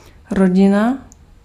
Ääntäminen
Synonyymit huishouden familie huisgezin Ääntäminen : IPA: [ɣǝ.zɪn] Tuntematon aksentti: IPA: /ɣə.ˈzɪn/ Haettu sana löytyi näillä lähdekielillä: hollanti Käännös Ääninäyte 1. rodina {f} Suku: f .